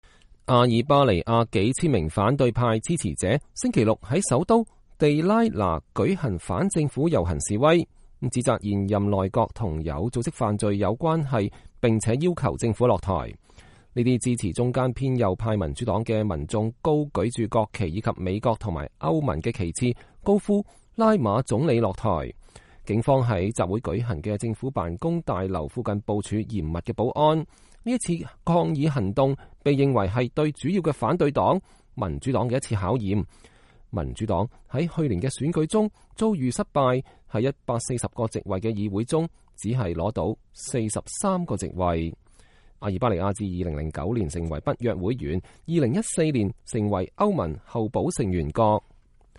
這些支持中間偏右派民主黨的民眾高舉著國旗以及美國和歐盟旗幟，高呼“拉馬(總理)下台！”